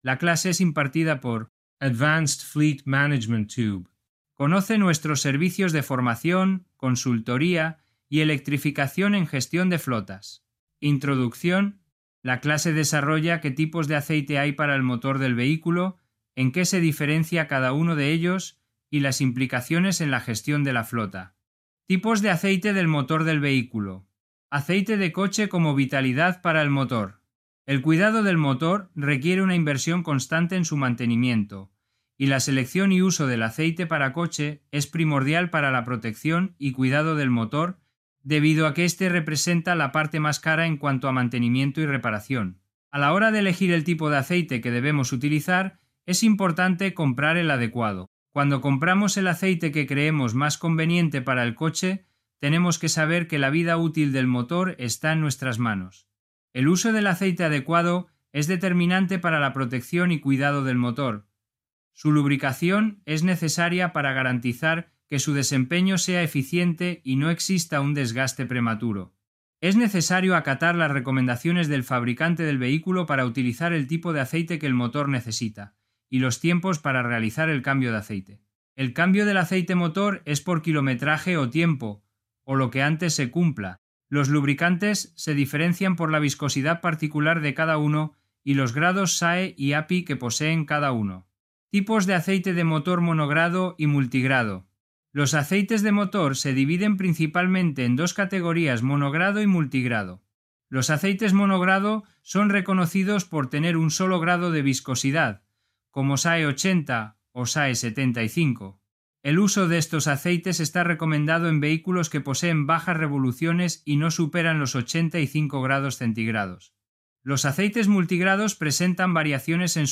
La clase desarrolla que tipos de aceite hay para el motor del vehículo, en qué se diferencia cada uno de ellos, y las implicaciones en la gestión de la flota.